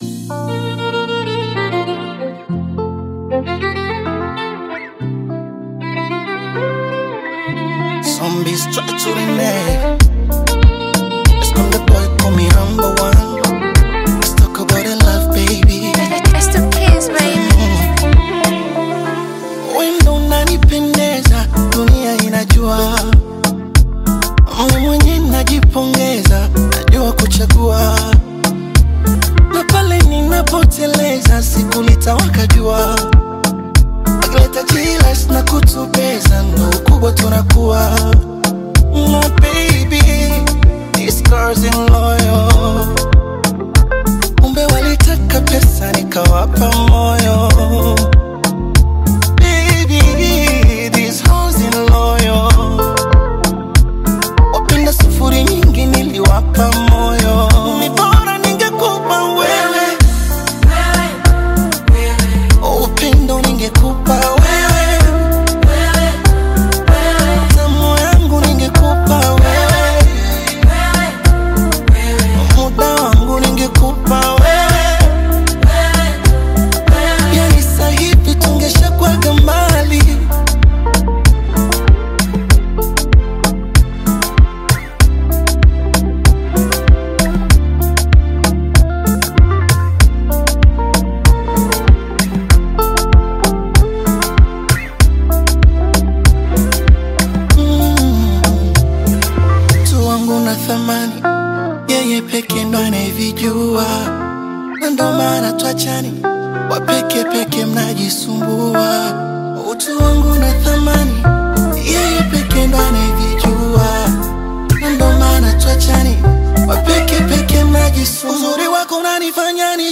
heartfelt Tanzanian Bongo Flava/Afro-Pop single